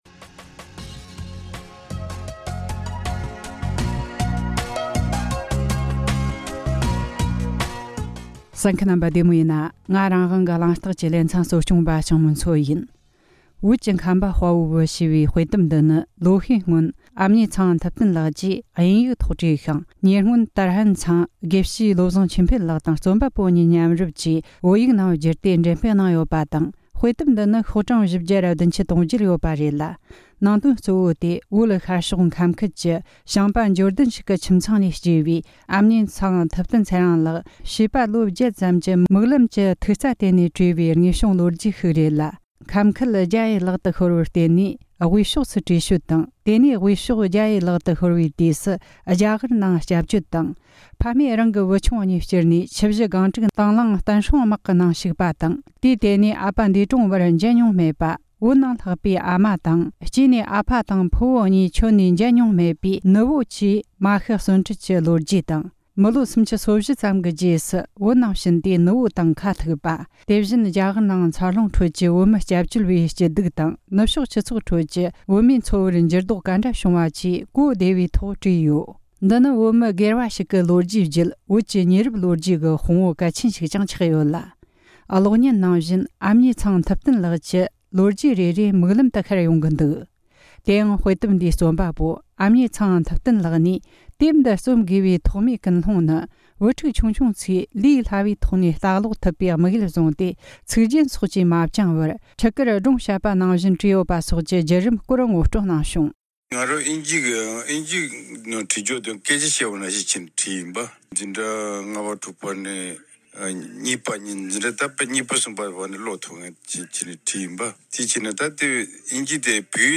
འབྲེལ་ཡོད་མི་སྣར་བཅར་འདྲི་བྱས་བར་གསན་རོགས་གནོངས།